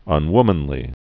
(ŭn-wmən-lē)